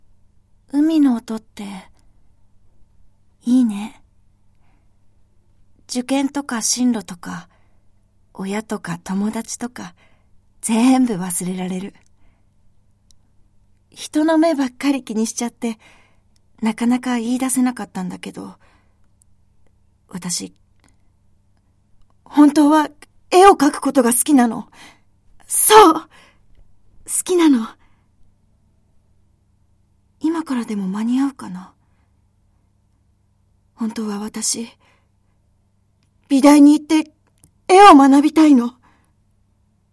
ボイスサンプル
セリフ4